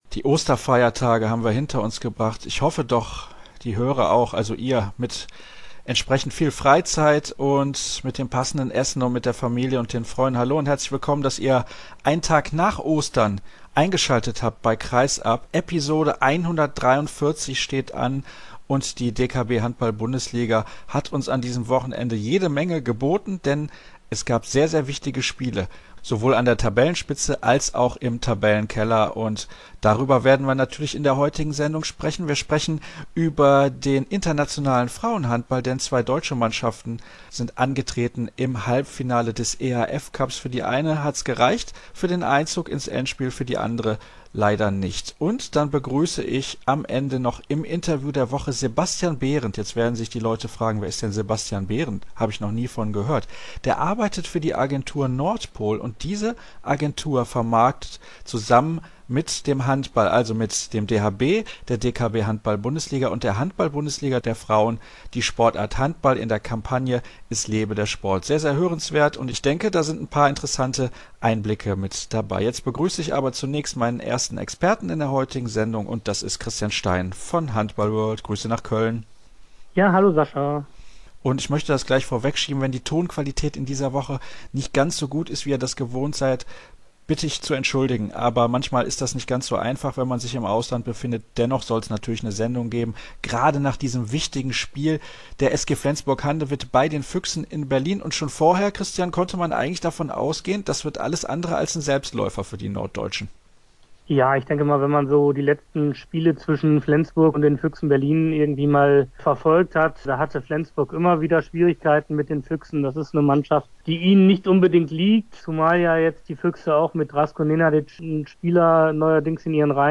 Im Interview der Woche ist Laura Steinbach zu Gast.